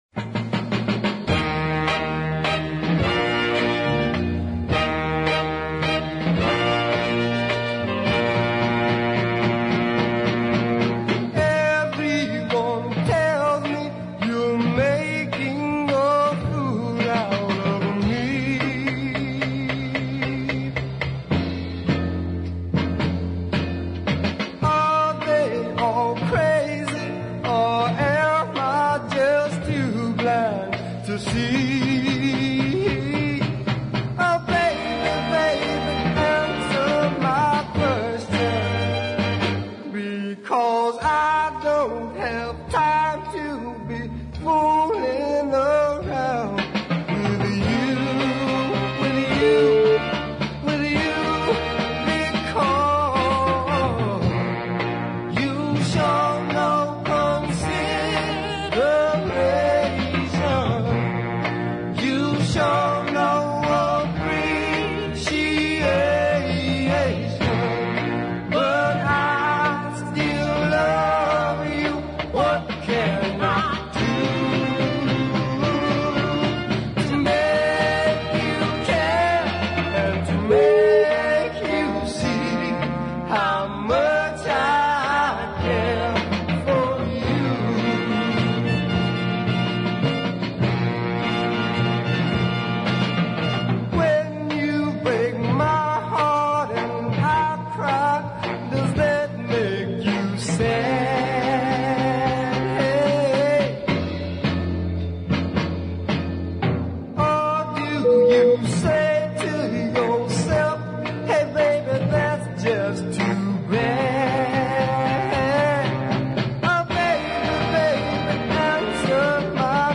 and both are strong southern style soul
I also like the waltz Listen
which features some fine horns and another class vocal.